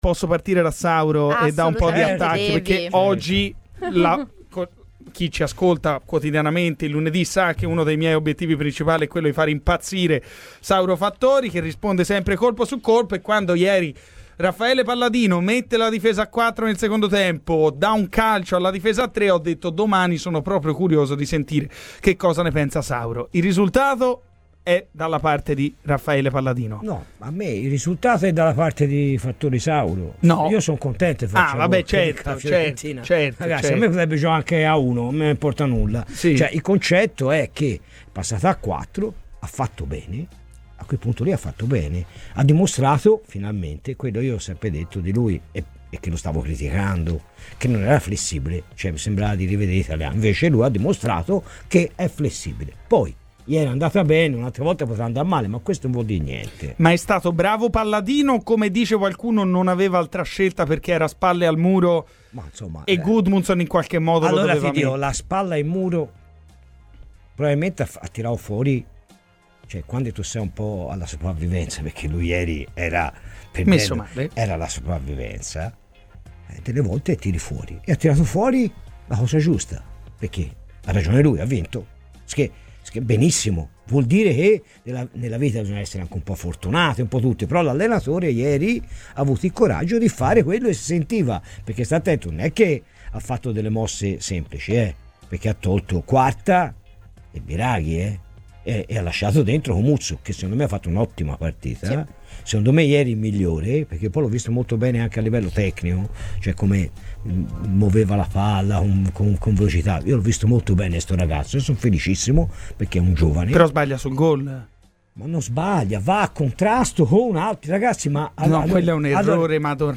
Ospite in studio durante la trasmissione "Garrisca al Vento"